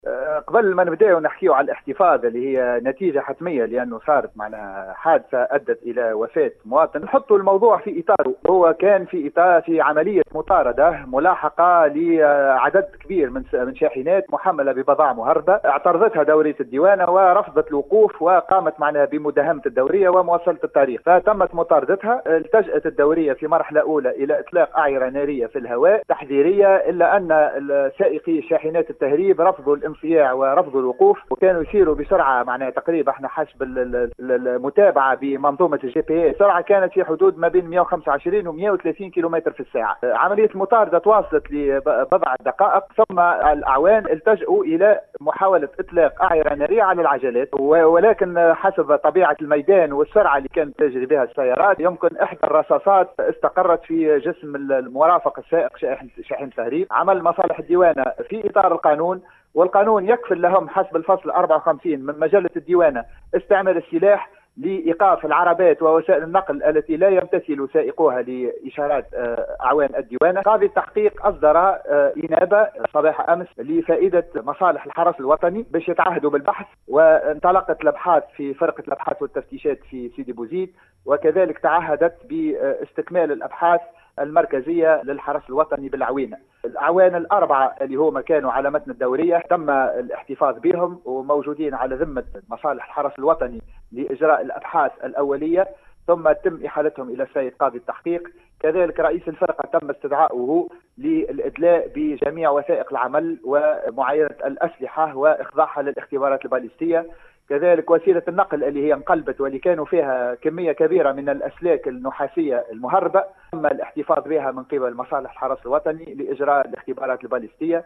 في إتصال بإذاعة السيليوم أف أم